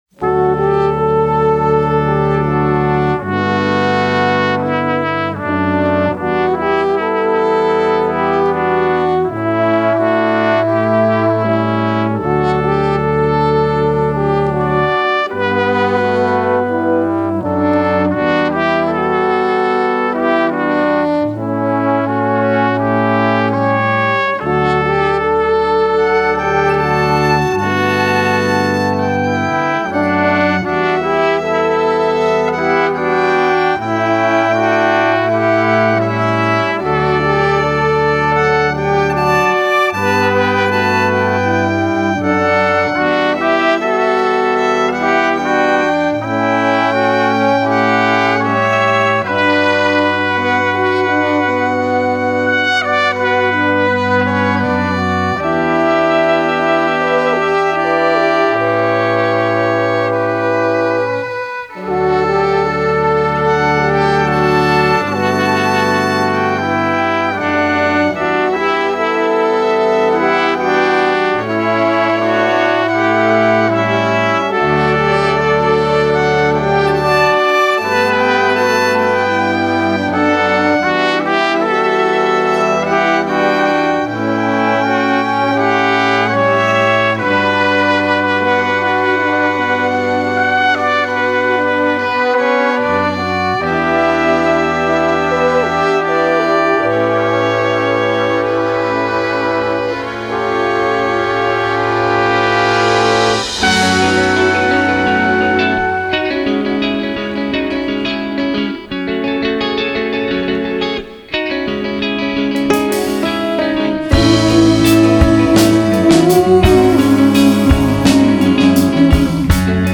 バンドレコーディング向けマルチトラックのミックスサービスとなります。
マルチトラックサンプルミックス